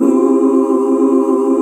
HUH SET C.wav